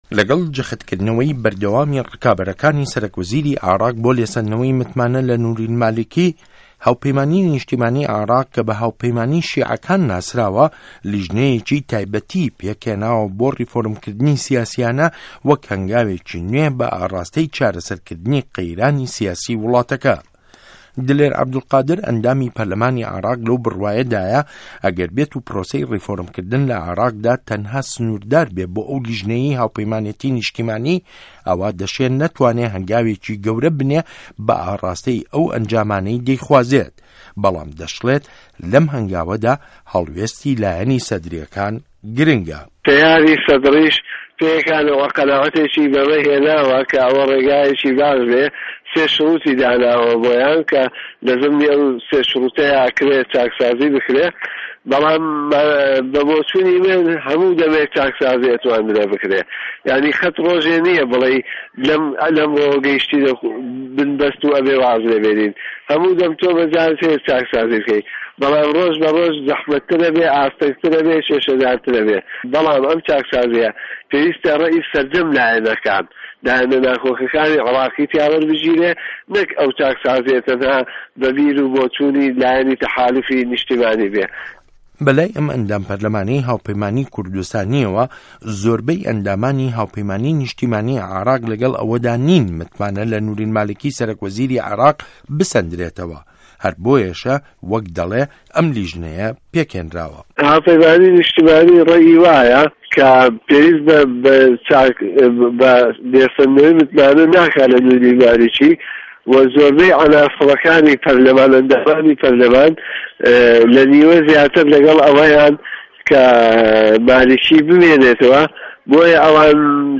ده‌قی ڕاپۆرتی ڕیفۆرم له‌ عێراق